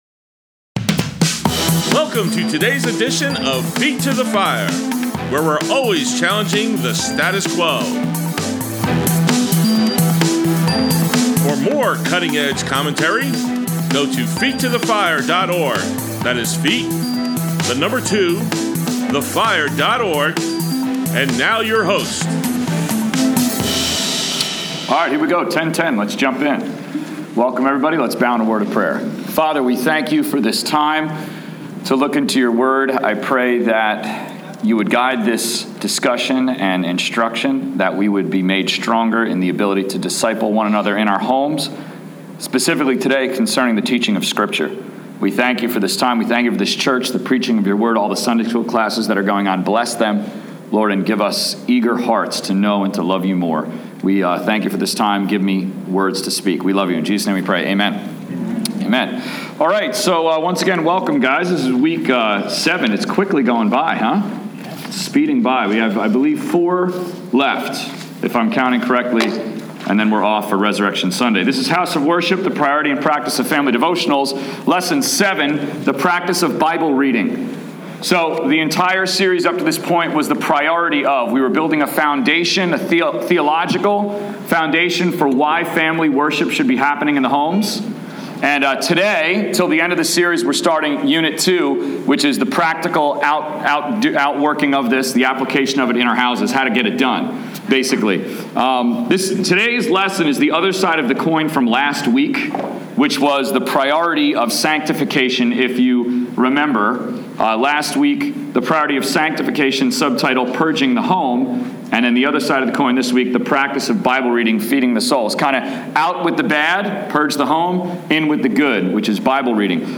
Grace Bible Church, Adult Sunday School, 2/28/16